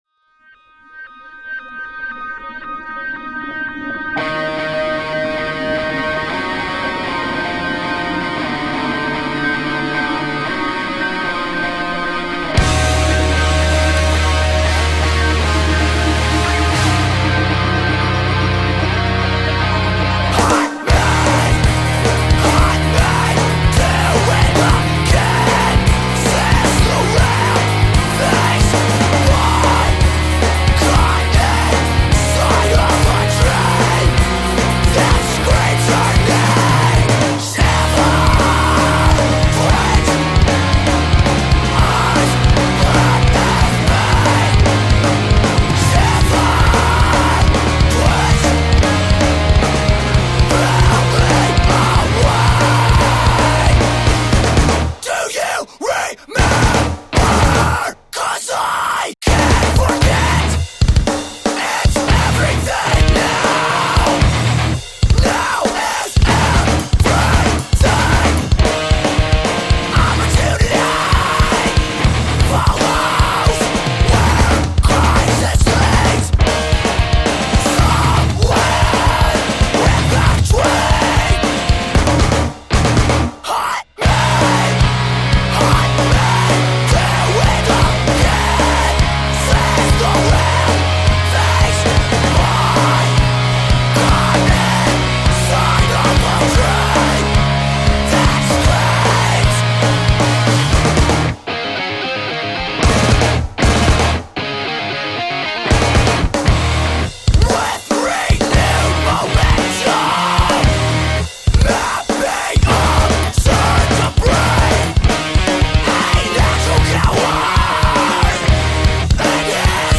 vocals are typical metalcore